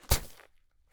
assets / sound / sfx / ball / sand2.wav
sand2.wav